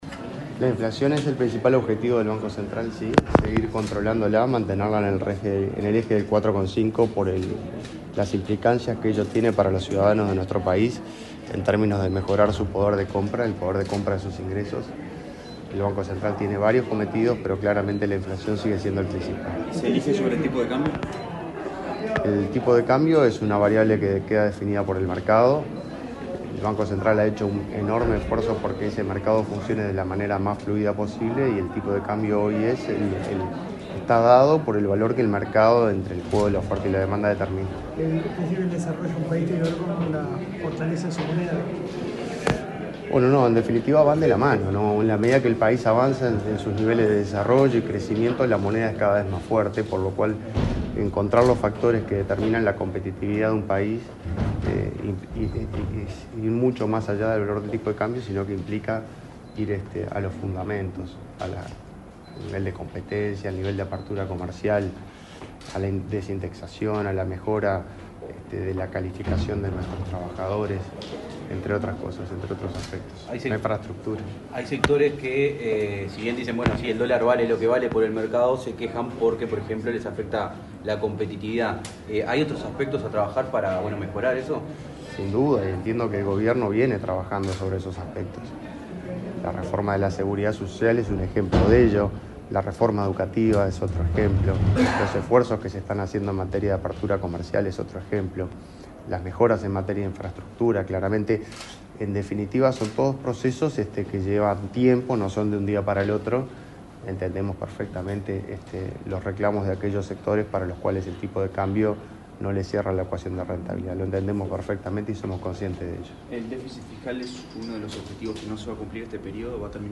Declaraciones del nuevo presidente del BCU, Washington Ribeiro
El nuevo presidente del Banco Central del Uruguay (BCU), Washington Ribeiro, dialogó con la prensa, luego de la ceremonia de su asunción como nuevo